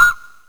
Perc 2 - Solo Dolo.wav